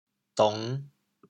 潮州 ding1 dong5 潮阳 diang1 dong5 饶平 ding1 dong5 汕头 ding1 dong5 潮州 0 1 潮阳 0 1 饶平 0 1 汕头 0 1